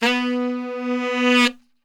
B 1 SAXSWL.wav